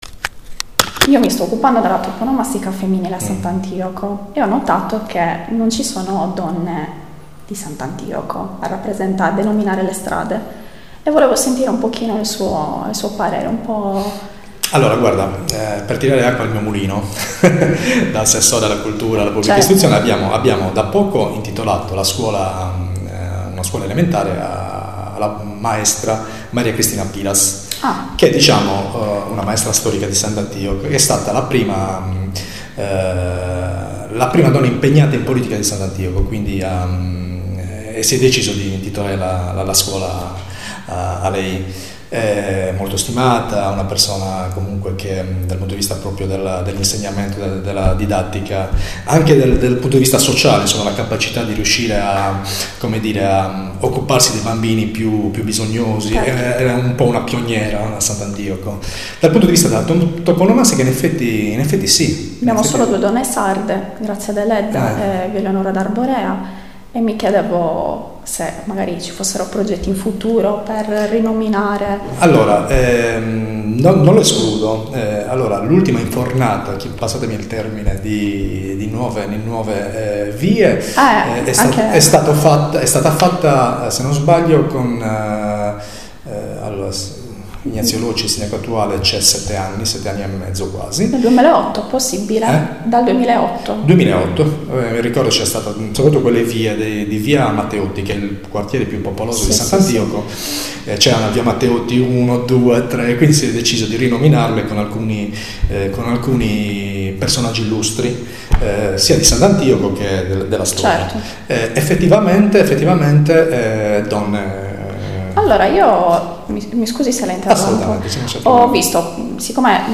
Intervista ad assessore alla cultura Gianluca Mereu
Luogo della registrazione Comune di Sant'Antioco